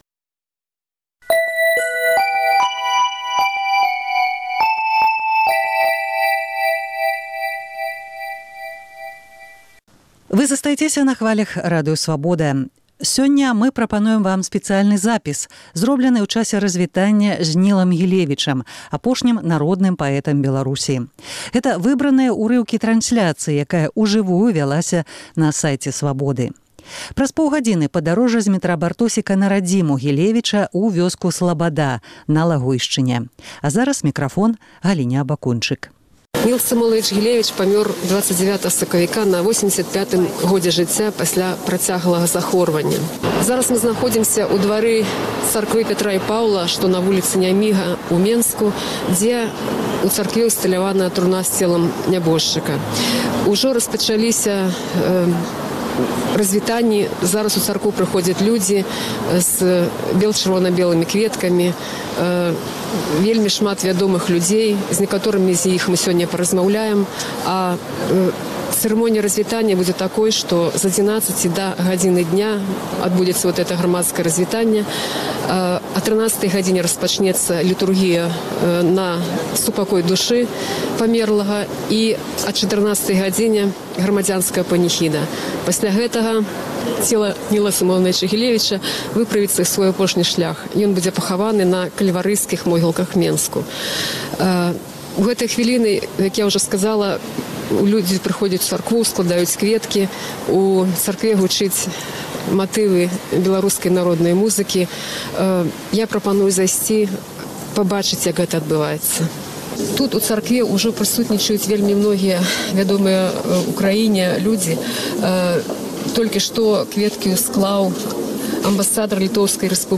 У Сьвята-Петрапаўлаўскім саборы ў Менску разьвіталіся з народным паэтам Беларусі Нілам Гілевічам.